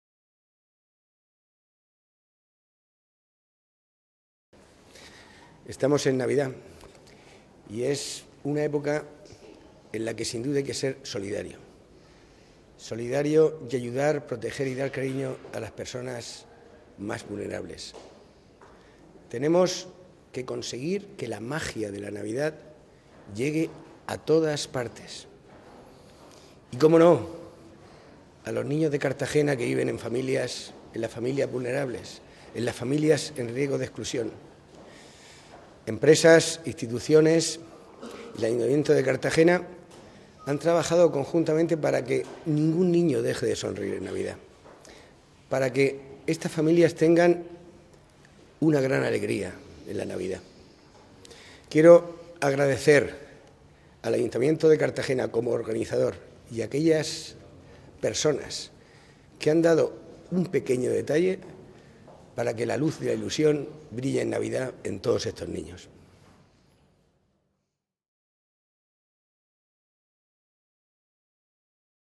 • Declaraciones del vicepresidente primero de la Asamblea Regional, Miguel Ángel Miralles